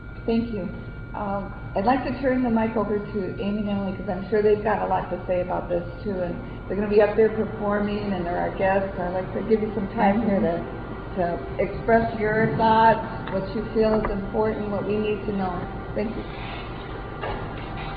lifeblood: bootlegs: 1997-09-08: honor the earth - buffalo, new york (press conference)